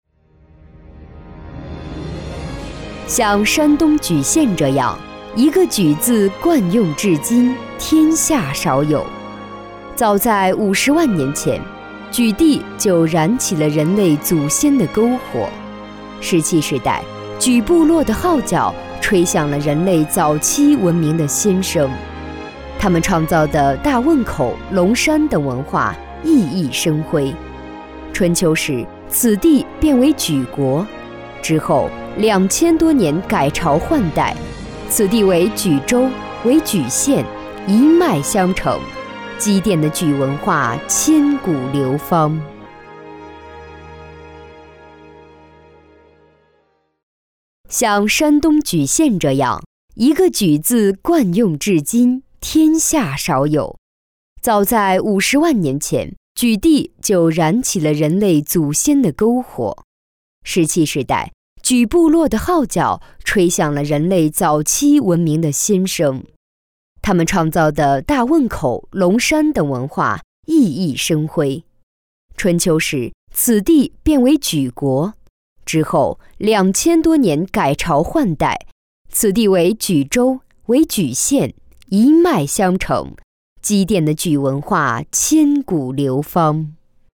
女9实惠知性-纵声配音网
女9 山东莒县（大气）.mp3